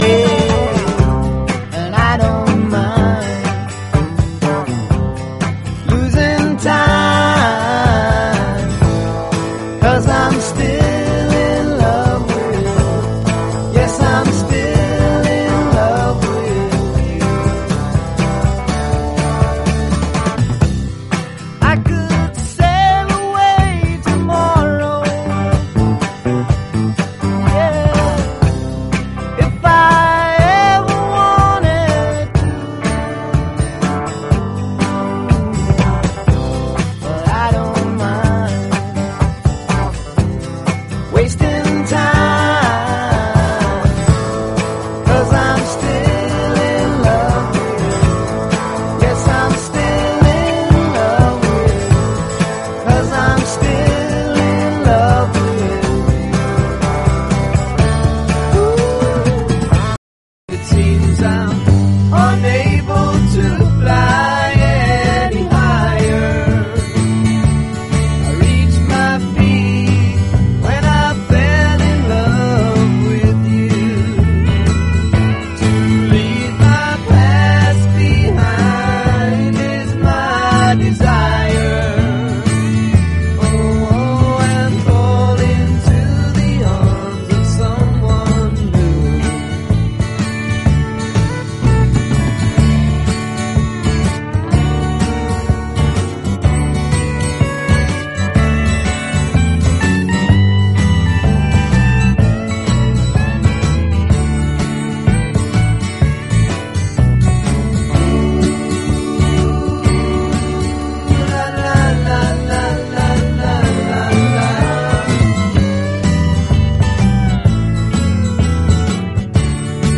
レイト60'Sロックのフィーリング溢れる、ファンキー・ビッグバンド！